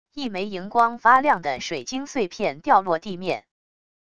一枚荧光发亮的水晶碎片掉落地面wav音频